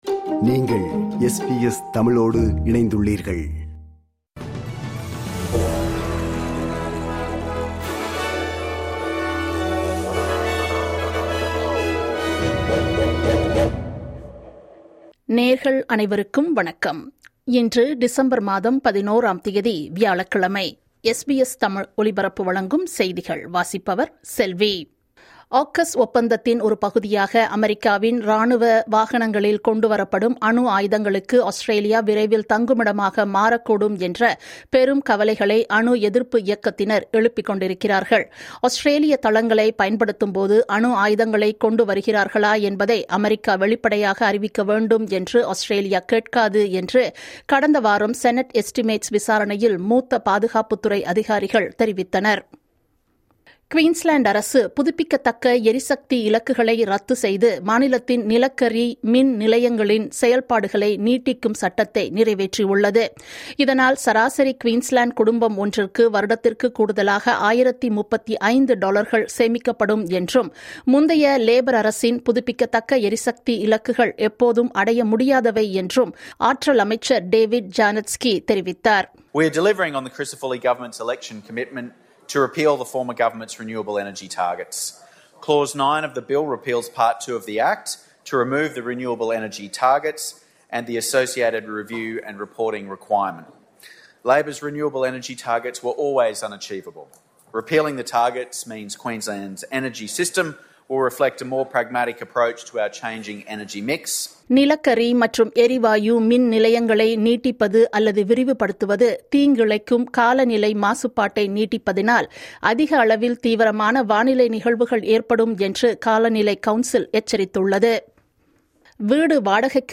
இன்றைய செய்திகள்: 11 டிசம்பர் 2025 வியாழக்கிழமை
SBS தமிழ் ஒலிபரப்பின் இன்றைய (வியாழக்கிழமை 11/12/2025) செய்திகள்.